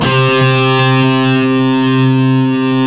Index of /90_sSampleCDs/Roland - Rhythm Section/GTR_Distorted 1/GTR_Power Chords